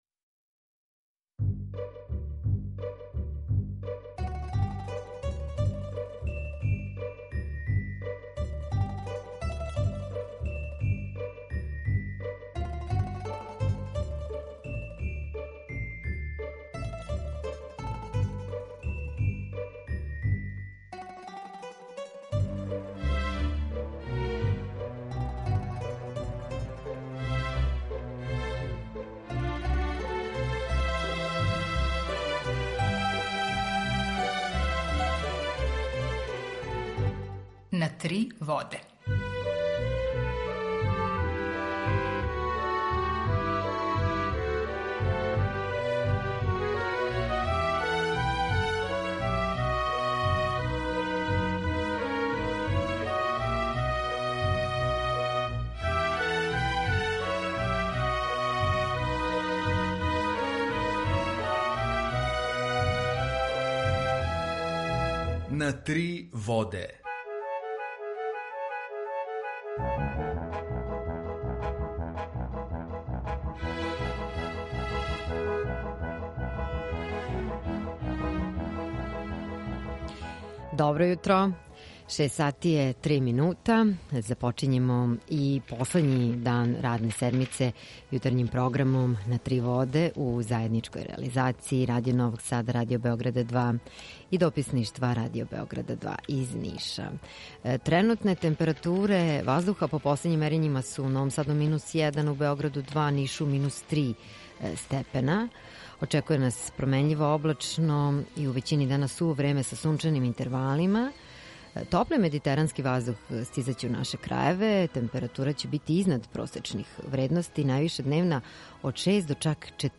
Емисију реализујемо заједно са студијом Радија Републике Српске у Бањалуци и Радијом Нови Сад
Јутарњи програм из три студија